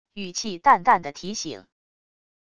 语气淡淡的提醒wav音频